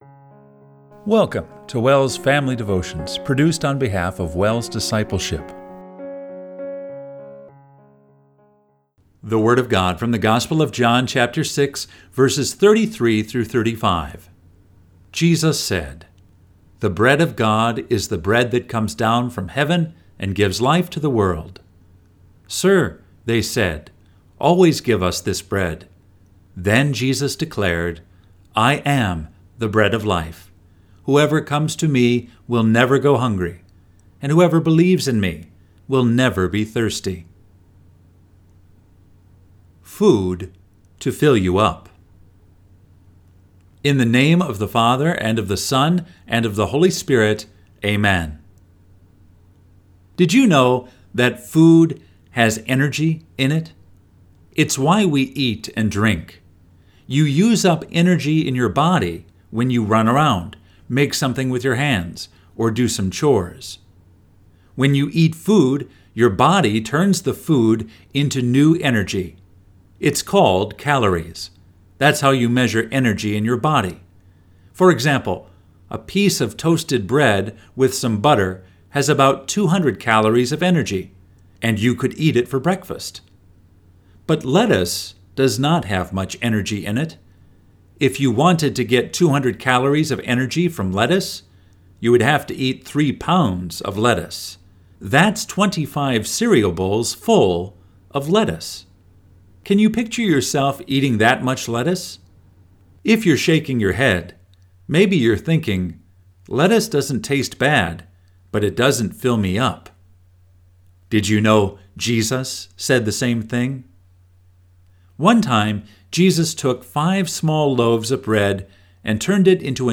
Family Devotion – August 16, 2024